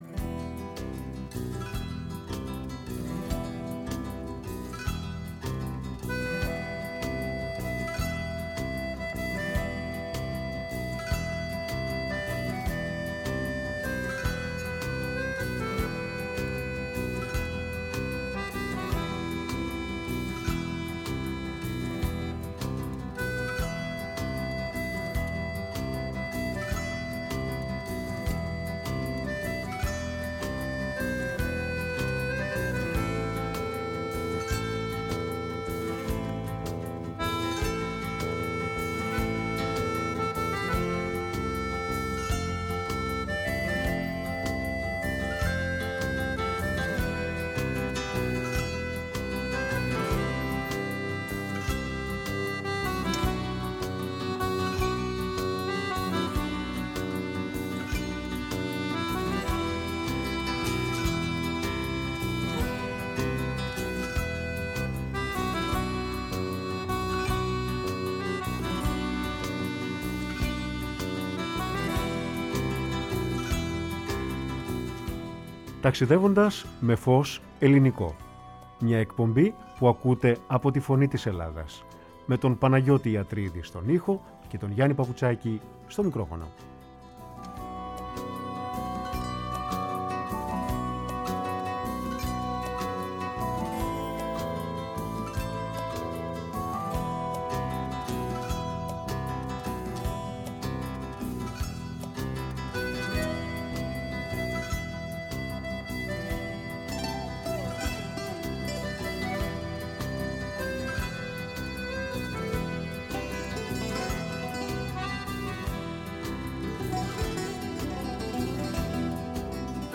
Στην εκπομπή ακούστηκε μουσική από το έργο του Γκουρτζίεφ, του μεγάλου αυτού Έλληνα συνθέτη και φιλόσοφου!
Η ΦΩΝΗ ΤΗΣ ΕΛΛΑΔΑΣ Ταξιδευοντας με Φως Ελληνικο Συνεντεύξεις